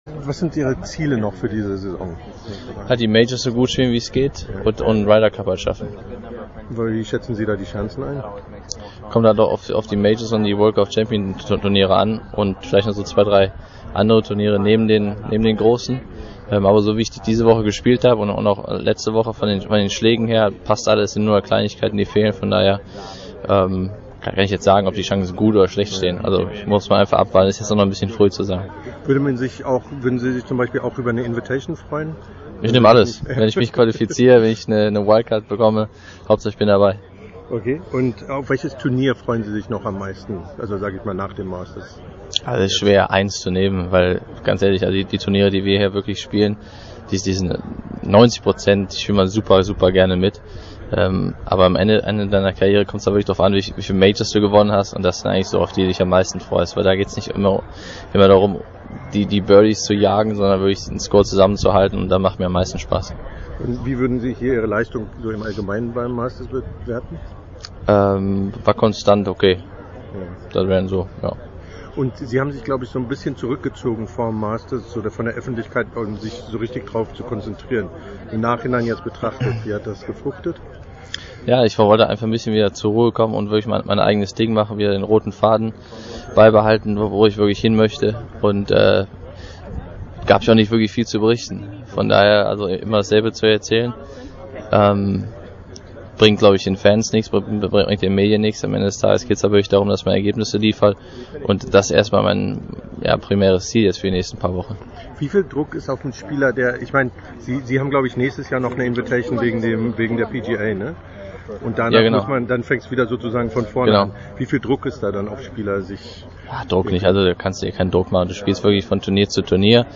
Martin Kaymer im Interview nach seiner Finalrunde beim Masters: Über Saisonpläne, Ryder Cup und den Druck, der auf ihn zukommt.